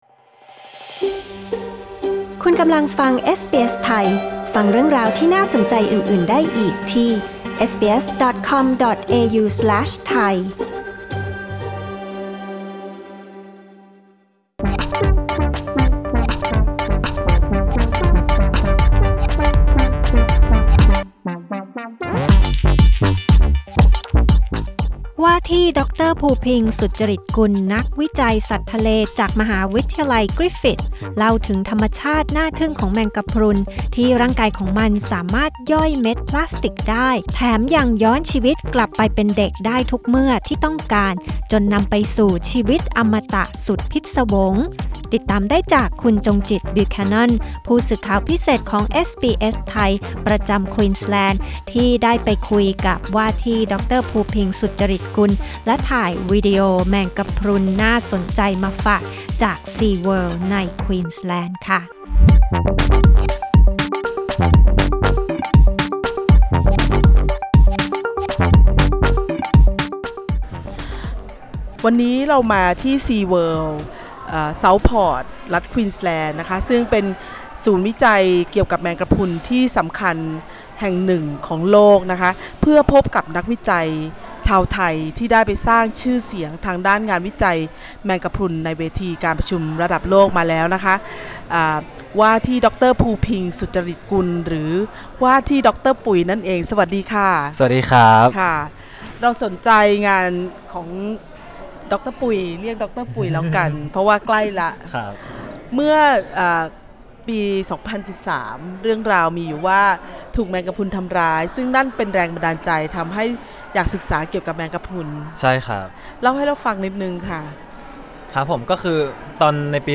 กดปุ่ม 🔊 ด้านบนเพื่อฟังสัมภาษณ์เรื่องนี้